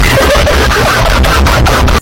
Sound Buttons: Sound Buttons View : Wheezing Laugh
wheezing-laugh.mp3